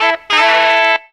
4 NOTE HARM.wav